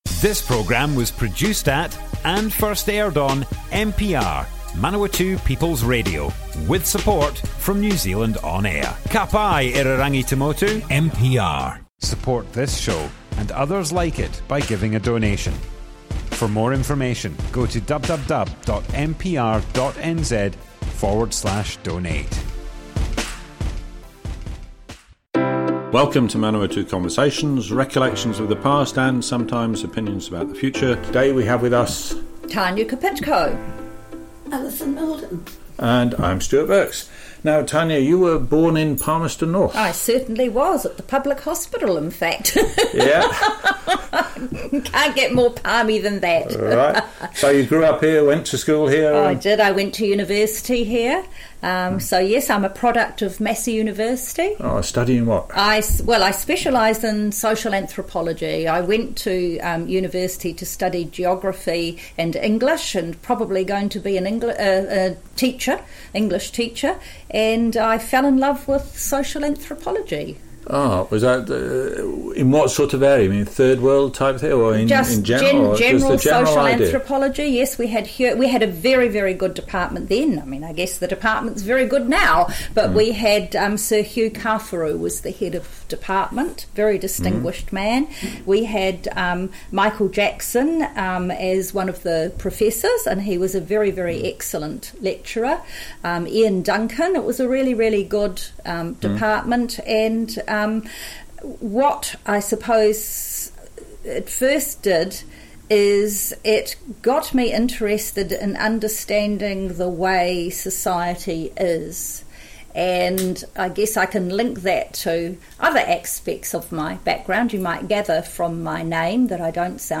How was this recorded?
Manawatu Conversations More Info → Description Broadcast on Manawatu People's Radio 29th March 2022.